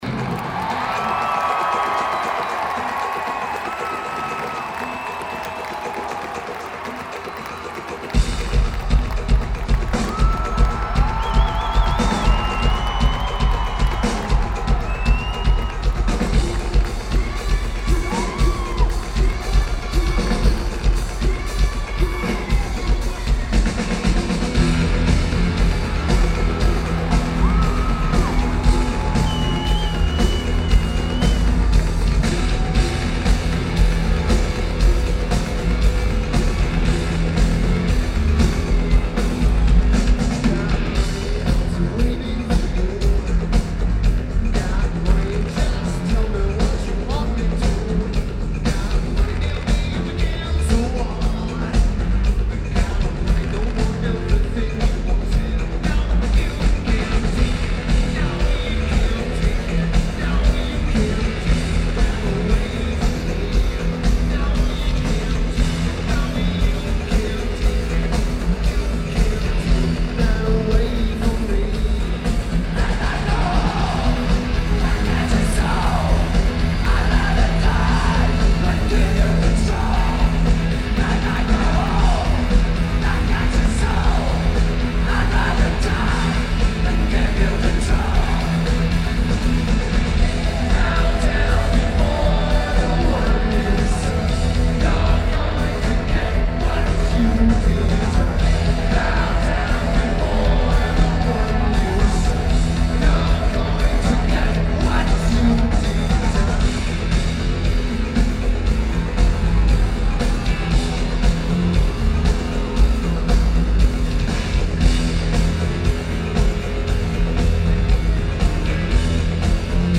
Rose Garden Arena
Drums
Guitar
Lineage: Audio - AUD (Sonic Studio DSM/6 + Tascam DR-2D)
Stating that the sonic source is rather, "bright."